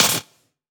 VEC3 Claps 079.wav